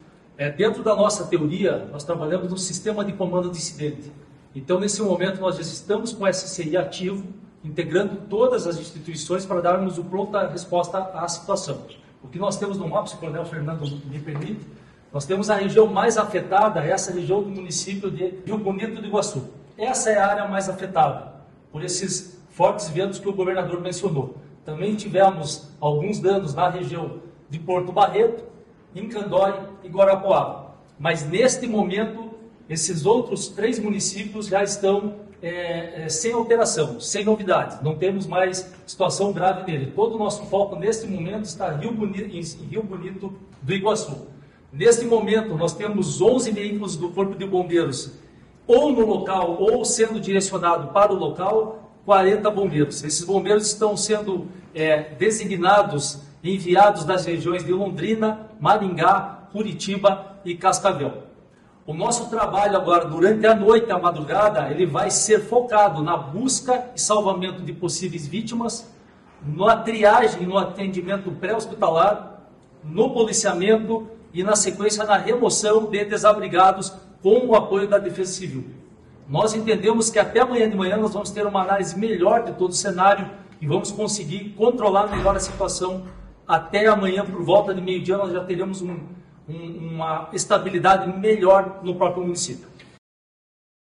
Sonora do subcomandante-geral do Corpo de Bombeiros do Paraná, coronel Jonas Emmanuel Benghi Pinto, sobre o primeiro atendimento aos municípios atingidos por tornado